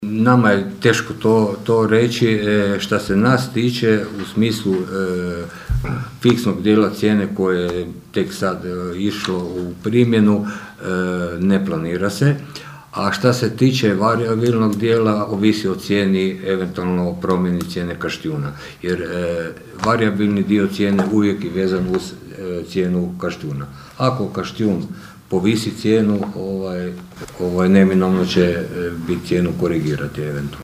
Na sinoć održanoj sjednici Općinskog vijeća Pićna, a prilikom razmatranja izvješća o radu Komunalnog poduzeća „1. Maj“ za prošlu godinu, postavilo se i pitanje hoće li, s obzirom na najnovije situacije i poskupljenje energenata, poskupjeti i njihova usluga, iako je ona s 1. ožujkom već poskupjela.